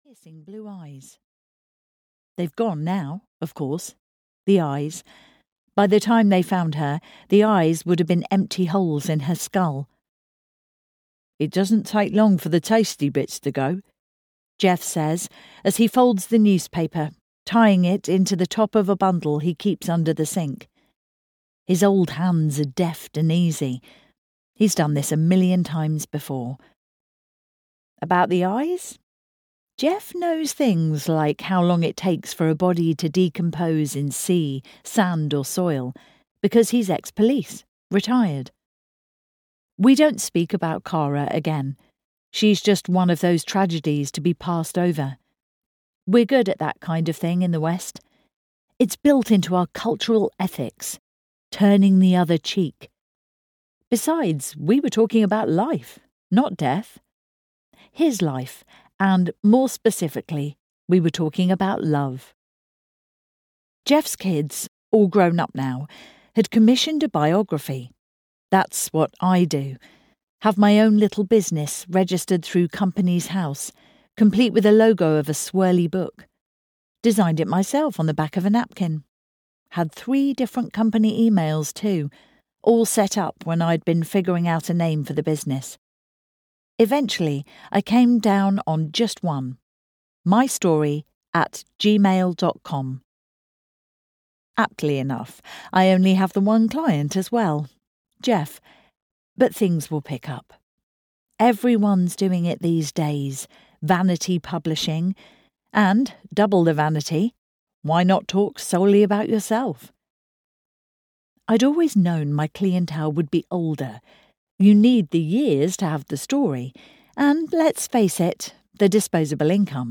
Reap What You Sow (EN) audiokniha
Ukázka z knihy